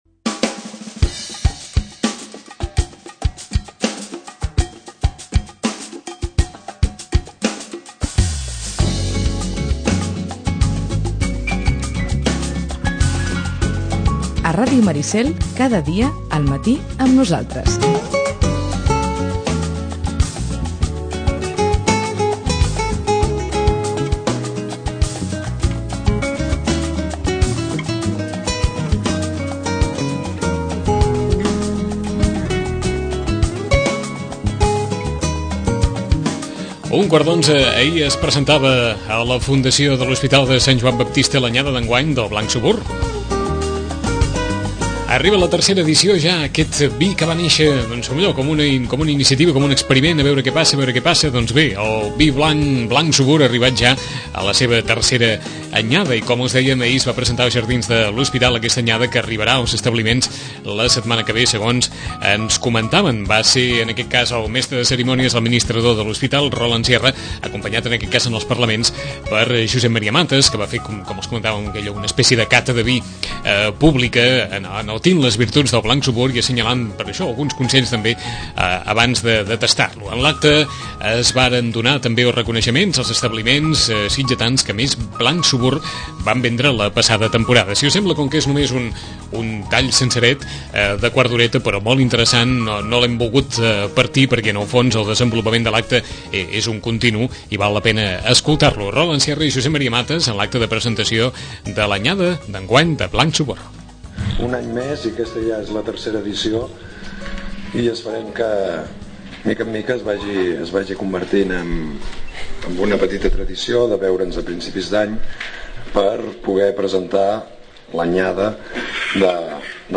Presentació del Blanc Subur